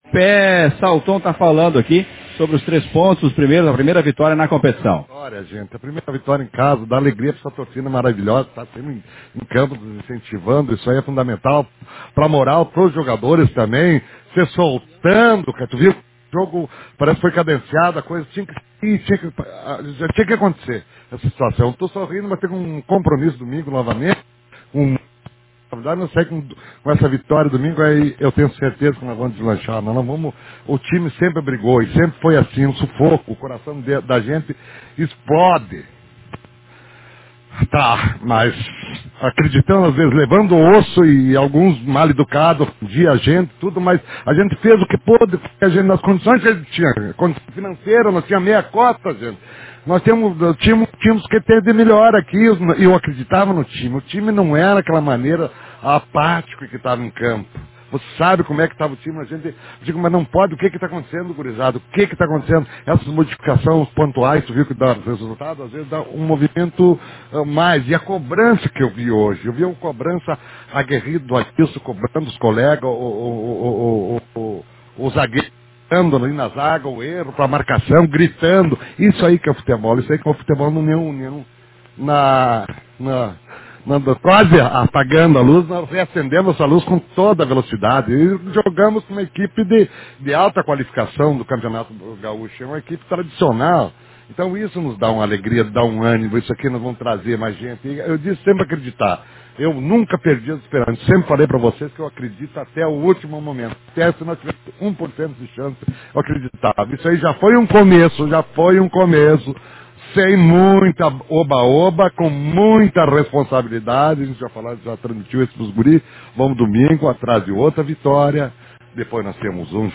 coletiva-juventude.mp3